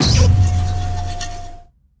Cri de Hurle-Queue dans Pokémon HOME.